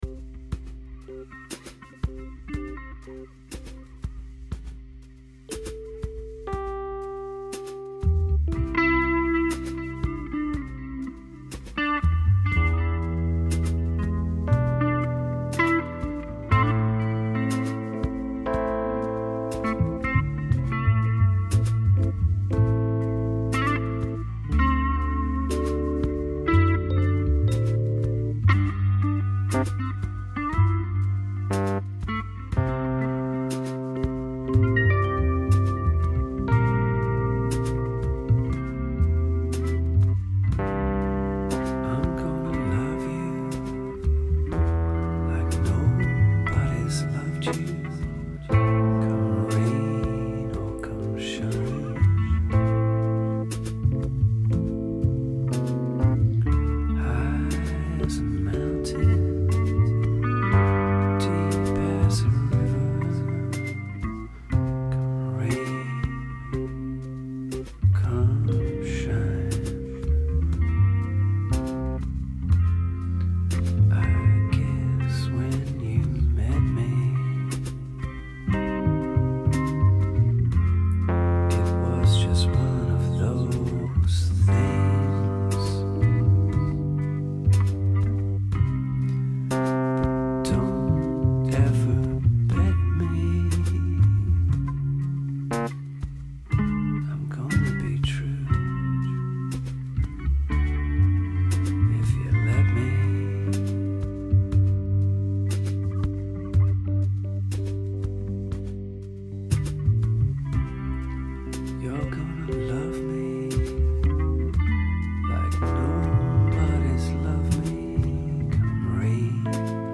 Melodic bass
including fractured Wurlitzer.